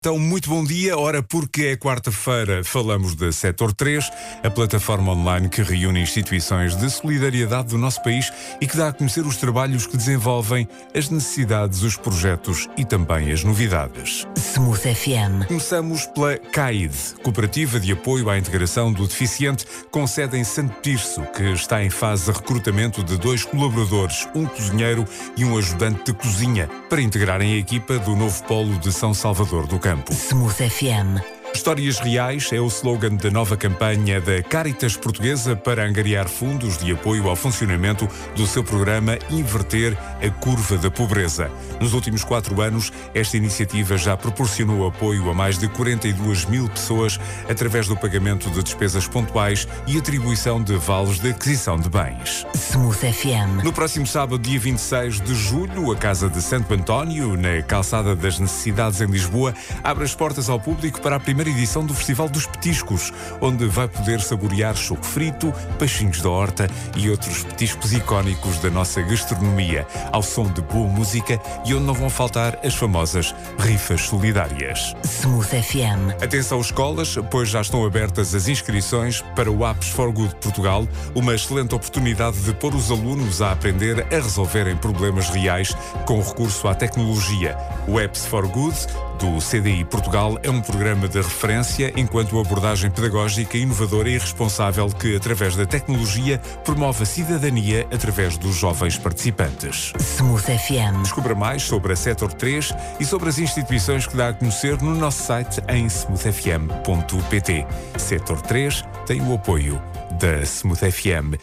23 julho 2025 Flash Smooth FM CAID | Caritas Diocesana de Beja | Casa de Santo António | CDI Portugal partilhar Facebook Twitter Email Apontamento rádio sobre a atividade desenvolvida por entidades-membros do Diretório Sector 3, que vai para o ar todas as quartas-feiras, às 8h, às 13h e às 17h. Conteúdos da edição de hoje, dia 23 de julho: CAID : Recrutamento de Cozinheiro e de Ajudante de Cozinha Caritas Diocesana de Beja : Campanha “Histórias Reais” Casa de Santo António : “Festival dos Petiscos” CDI Portugal : “Apps for Good Portugal” Oiça a gravação do spot rádio no ficheiro anexo Anexos Sector 3 - SMOOTH 23 julho 2025.mp3 Voltar